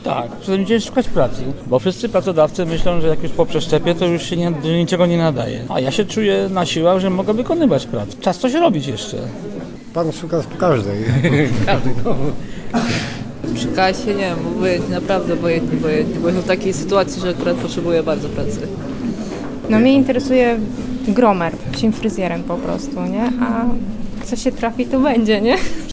5. Targi Pracy w Polkowicach
A co mówili poszukujący pracy?